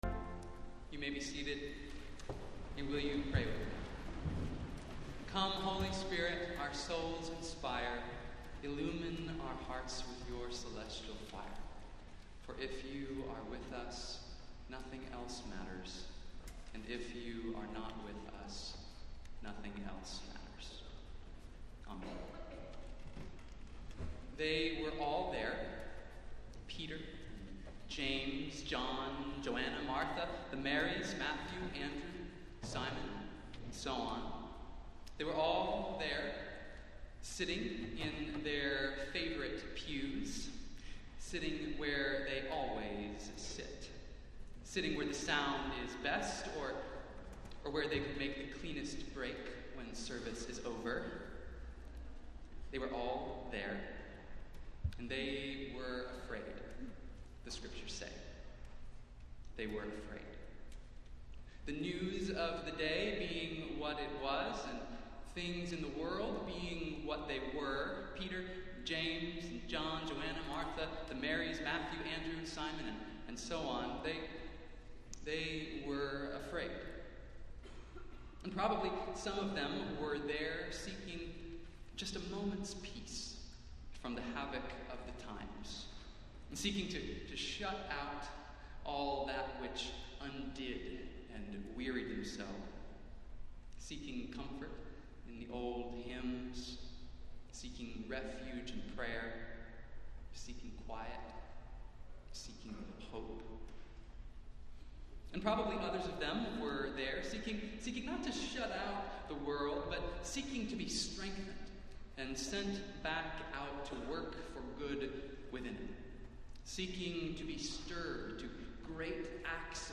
Festival Worship - Youth and Confirmation Sunday